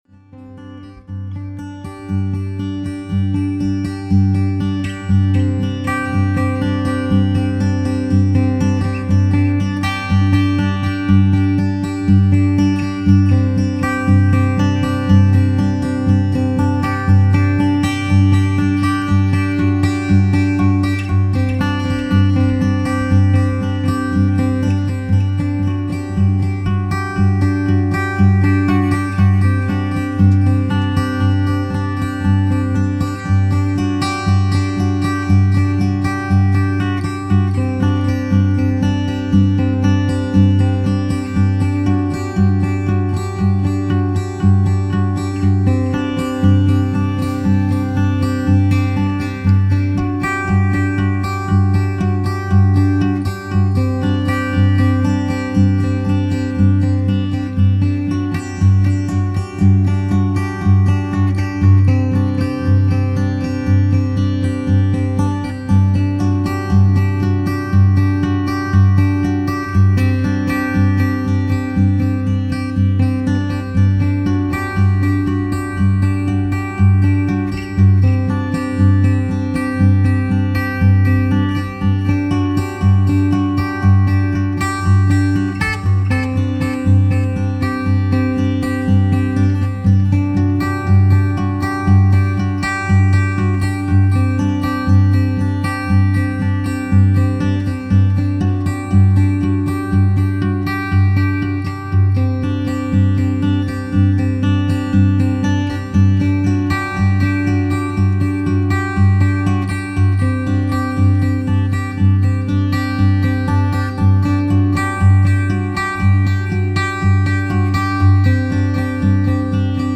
Genre : Ambient